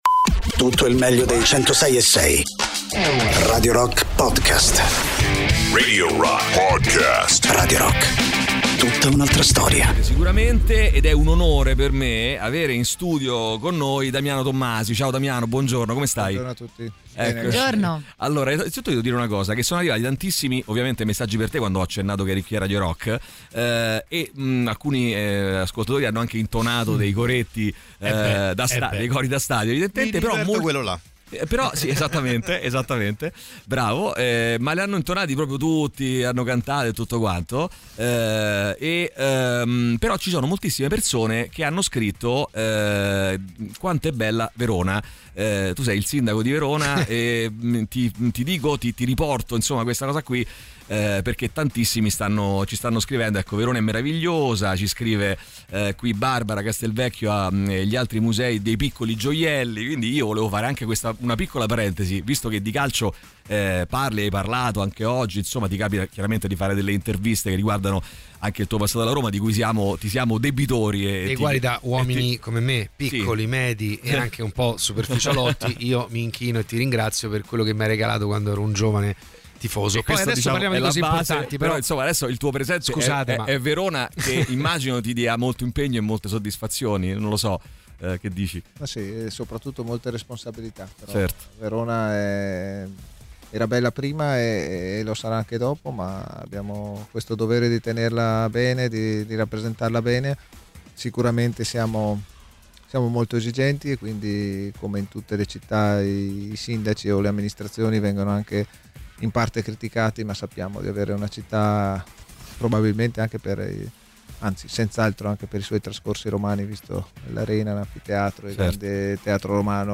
Interviste: Damiano Tommasi (04-12-25)